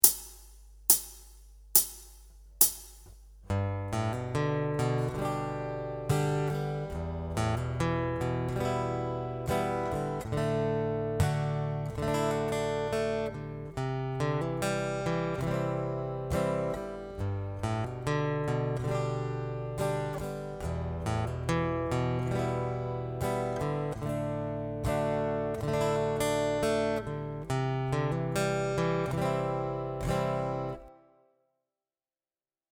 Acoustic Rhythm Guitar Chord Embellishment 1
In the progression above, I have created some simple embellishments using notes within the chord itself. As you can hear this creates a really cool sound.